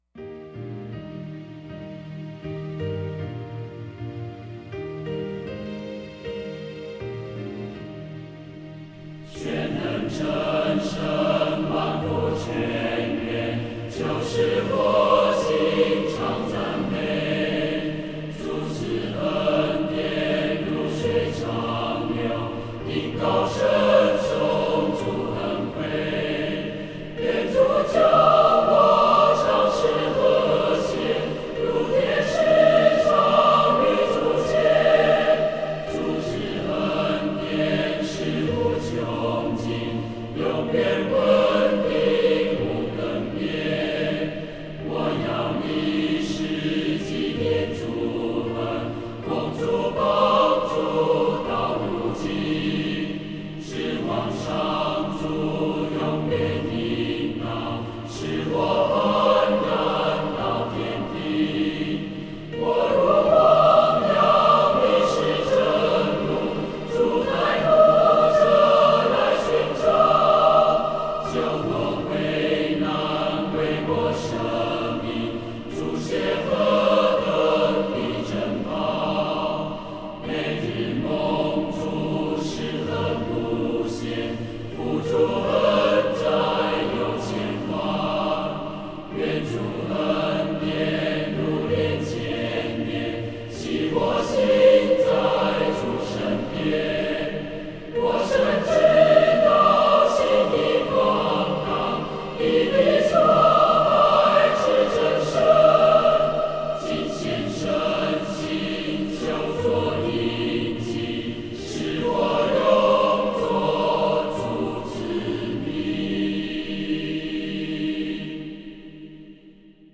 这首诗的曲调有很强烈的民族风味，全曲只有两句，虽然简单，却能打动人心。